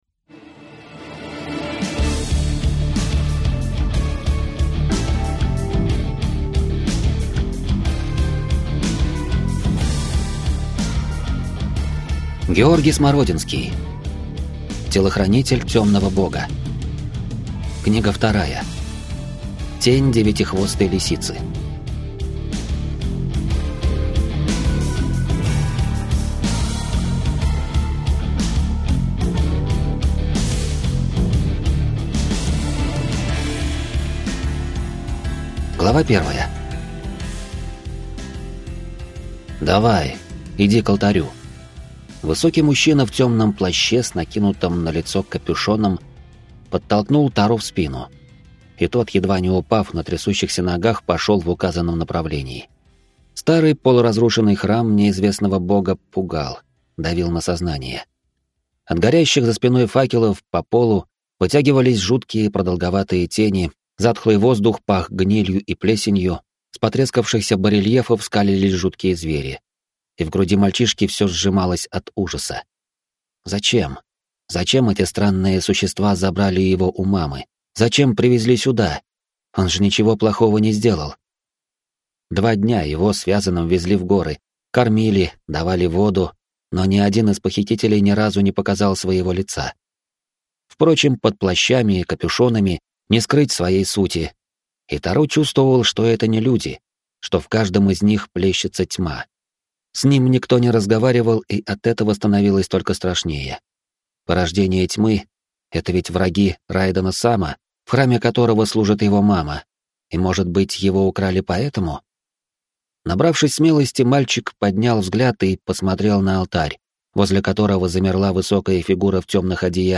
Аудиокнига Тень девятихвостой лисицы | Библиотека аудиокниг